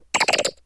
Grito de Clauncher.ogg
Grito_de_Clauncher.ogg.mp3